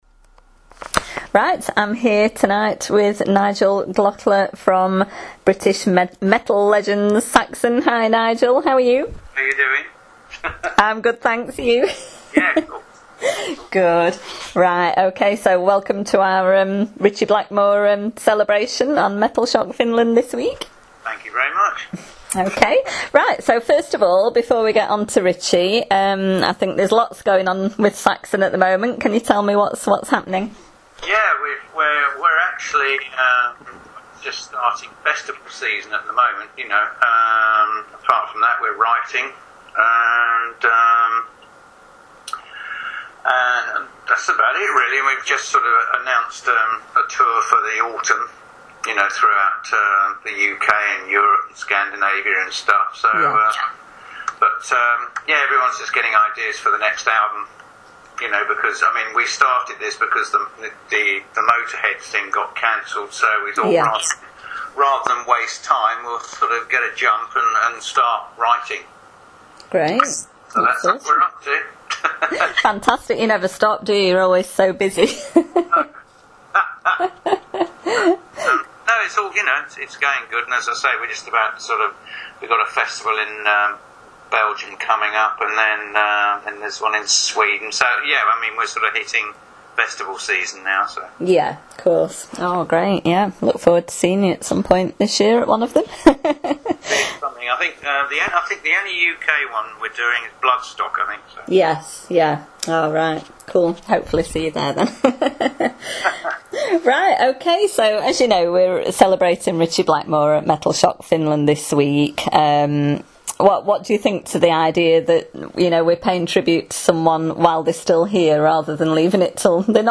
Interview With Nigel Glockler, SAXON: “My party piece was singing ‘Child In Time’, I could get all the high notes!”
Next up is a chat with drummer from NWOBHM legends SAXON and all round cool guy Nigel Glockler.